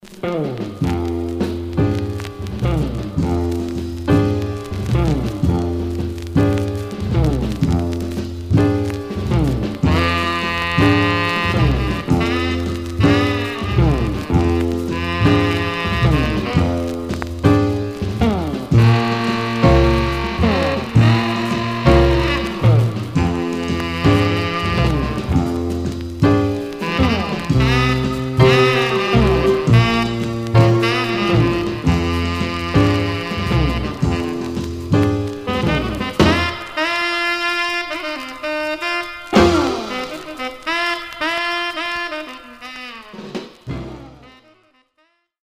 Mono
R&B Instrumental